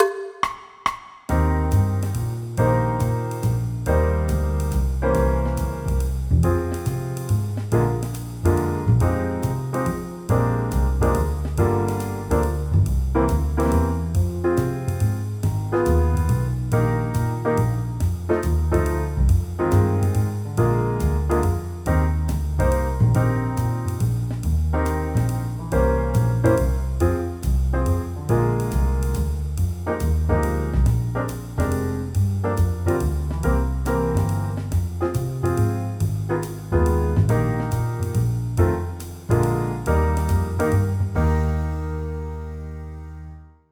Accompaniment Music Files